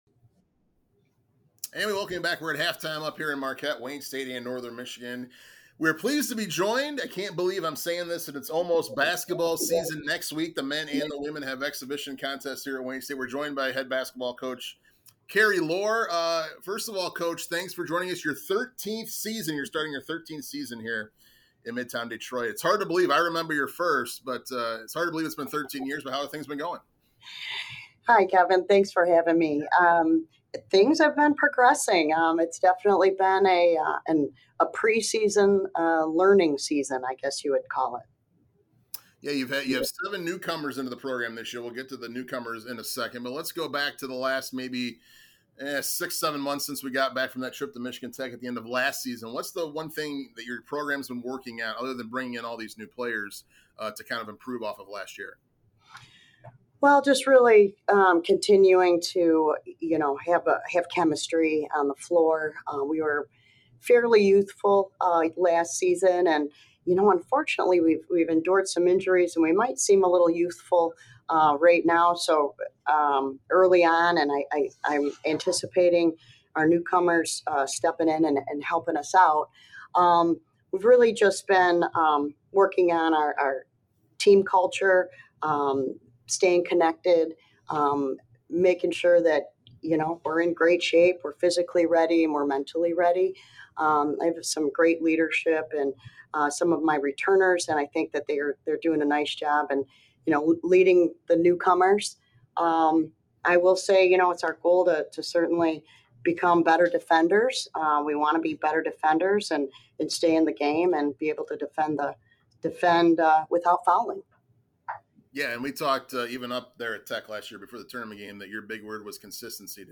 Halftime interview